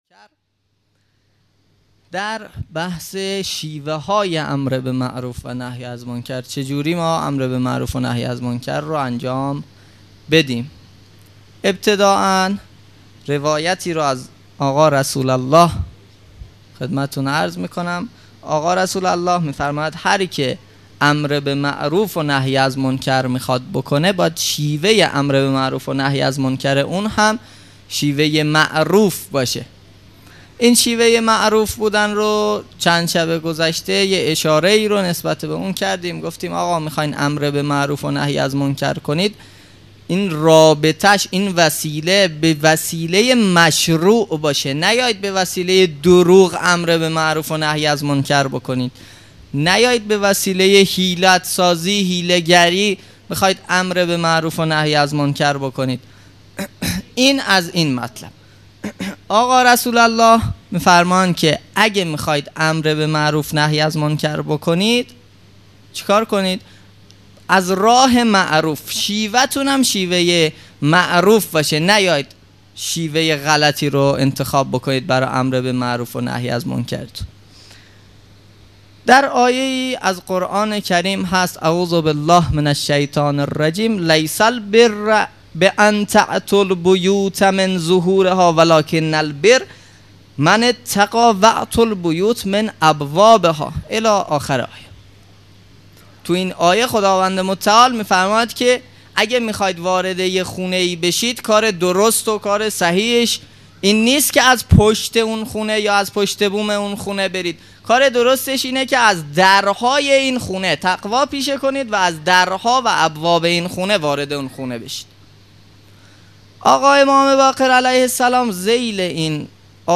مراسم عزاداری محرم الحرام ۱۴۴۳_شب نهم